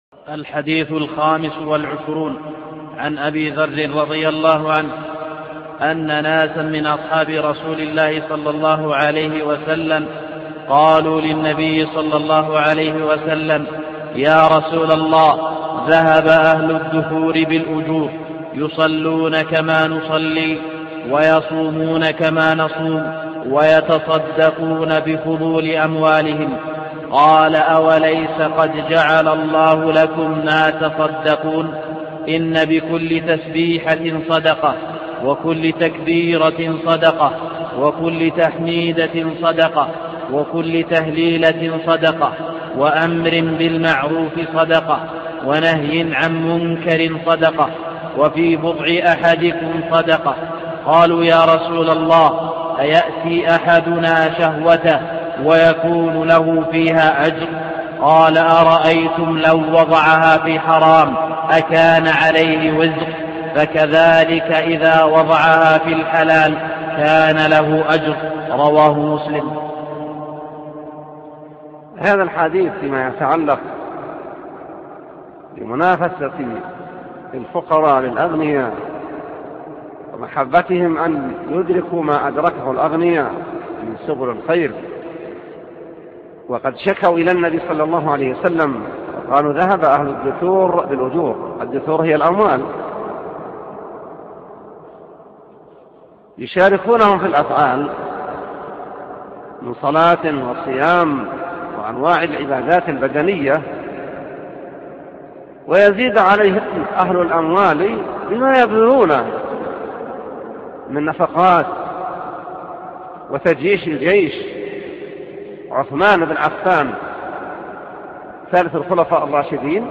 25 – شرح حديث إن بكل تسبيحة صدقة – الشيخ : صالح اللحيدان